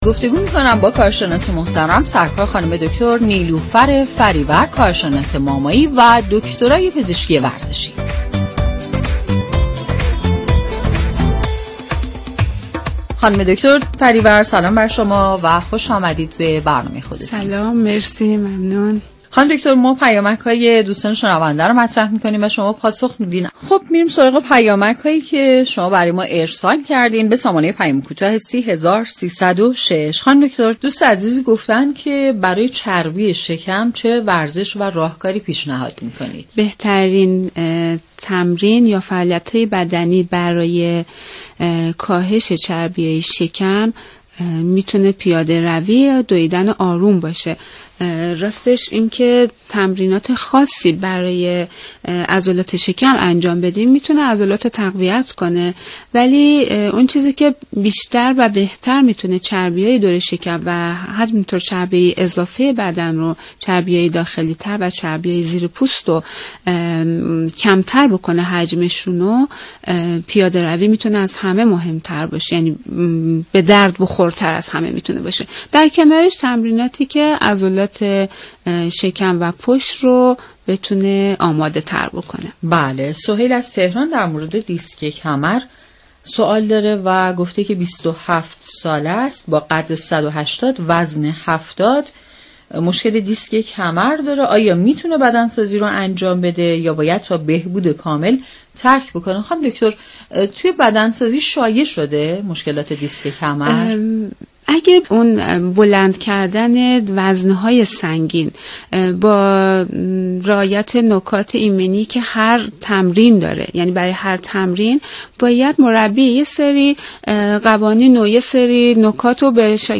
این برنامه همه روزه به جز جمعه ها، ساعت 13:45 به مدت 15 دقیقه از شبكه رادیویی ورزش تقدیم شنوندگان می شود.